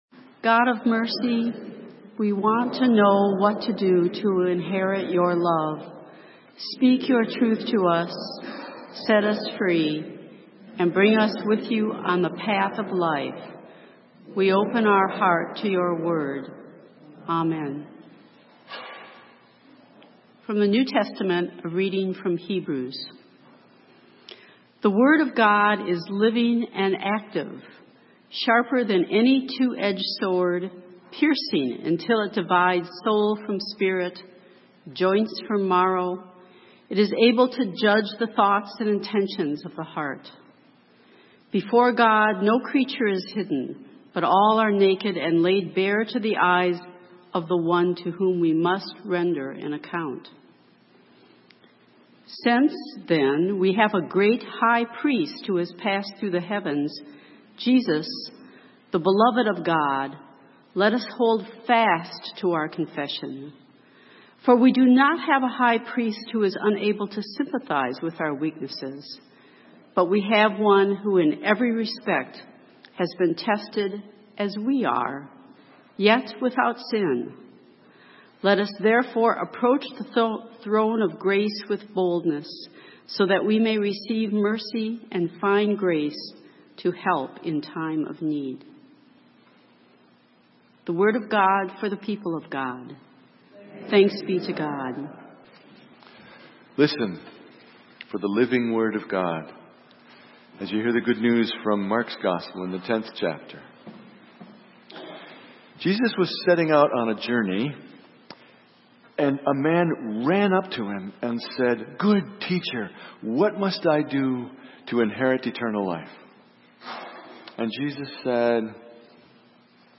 Sermon: Getting Free - St. Matthew's UMC